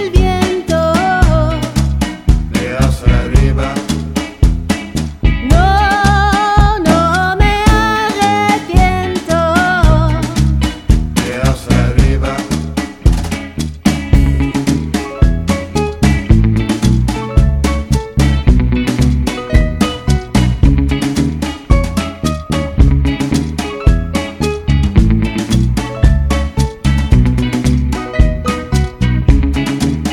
Rock et variétés internationales